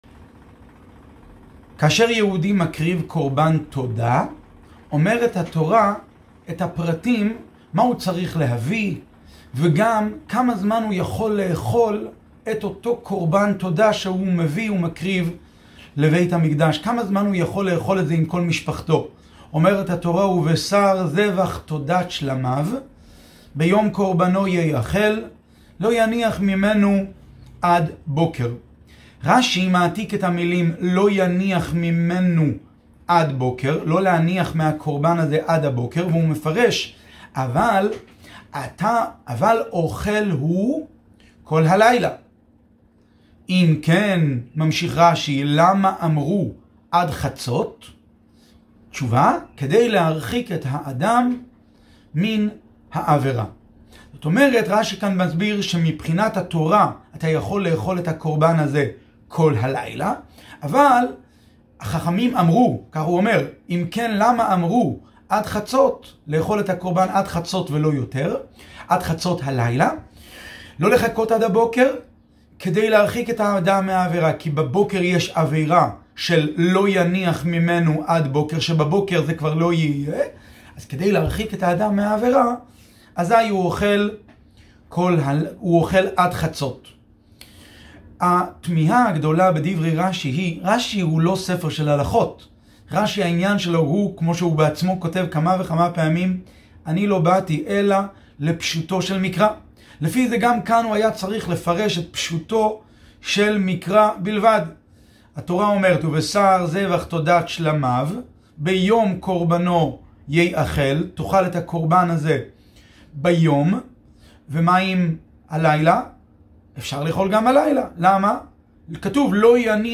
שיעור בעיון עברית